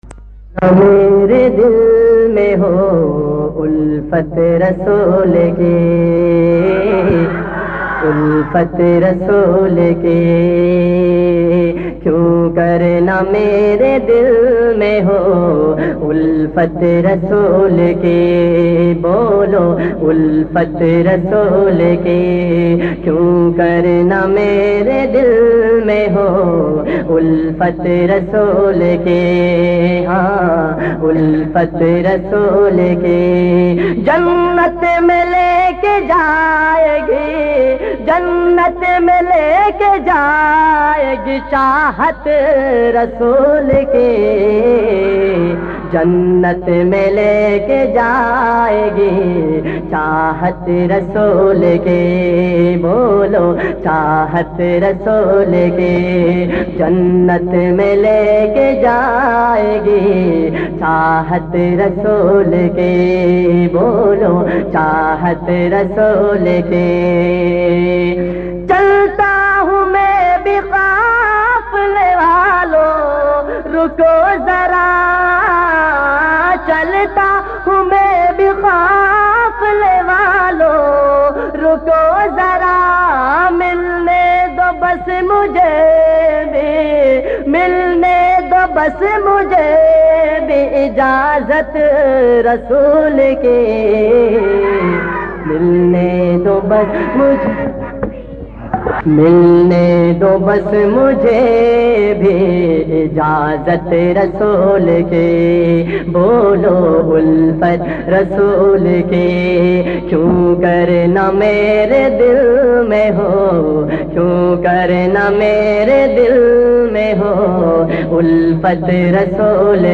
in a Heart-Touching Voice
His amazing accent draws in his followers.